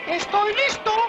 Worms speechbanks
Yessir.wav